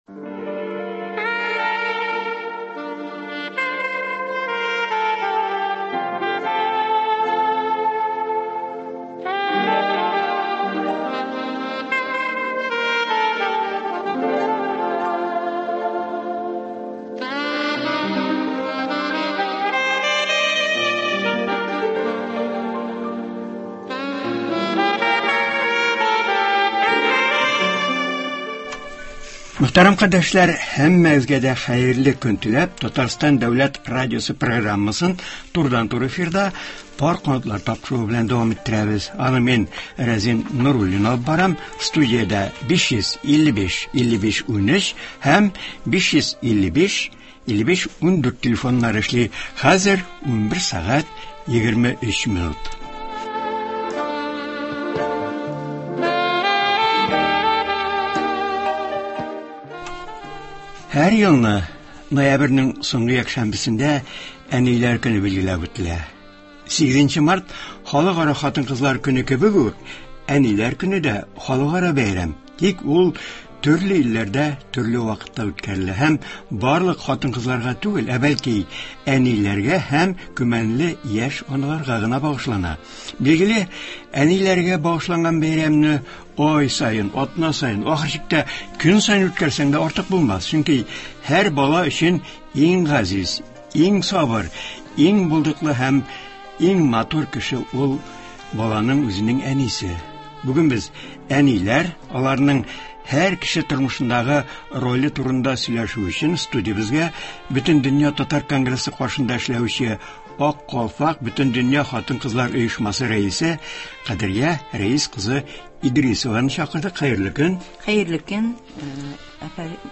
турыдан-туры эфирда
тыңлаучылар сорауларына җавап бирәчәк